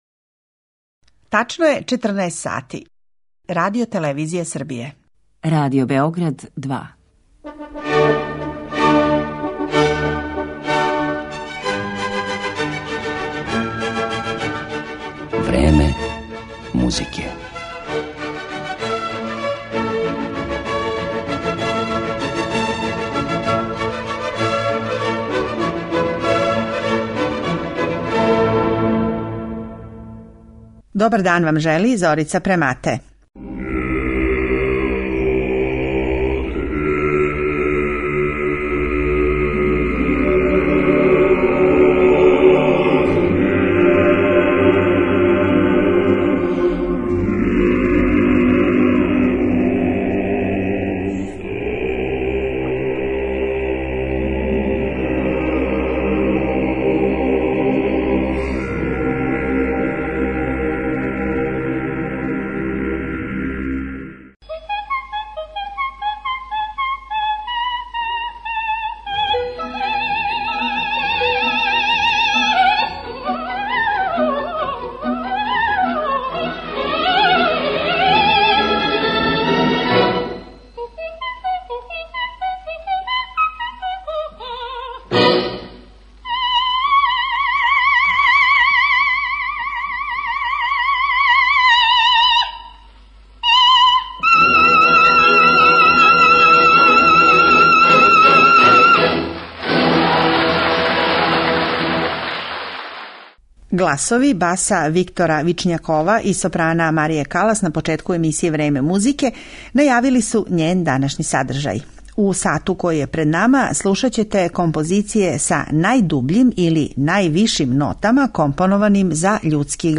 Биће то арије из опера ‒ од Монтевердија и Моцарта до Росинија, Вердија и Делиба.